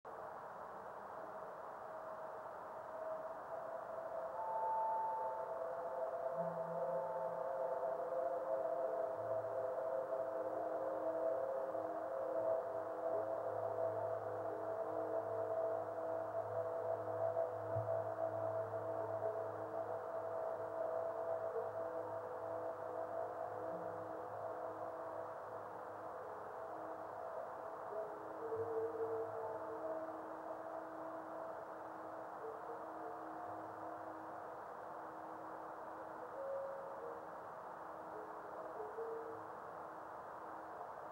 video and stereo sound: